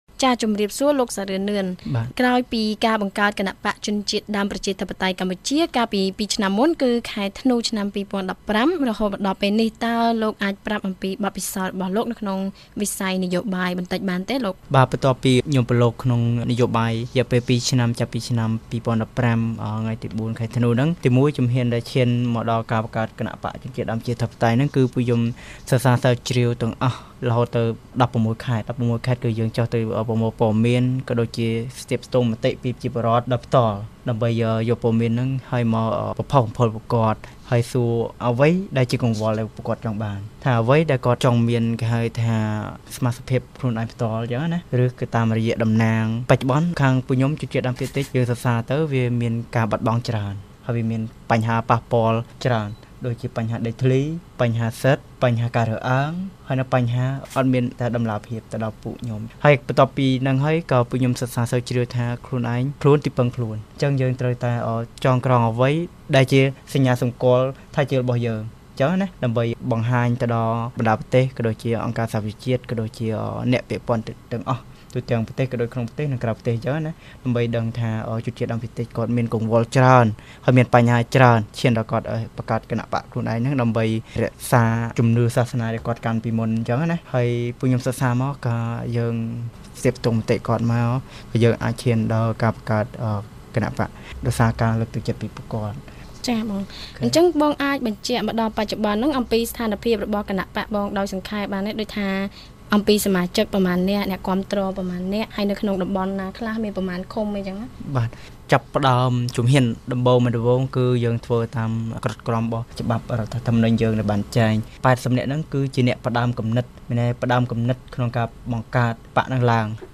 បទសម្ភាសន៍ VOA៖ គណបក្សជនជាតិដើមប្រជាធិបតេយ្យកម្ពុជារំពឹងទទួលបានការគាំទ្រច្រើន